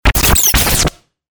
FX-1113-BREAKER
FX-1113-BREAKER.mp3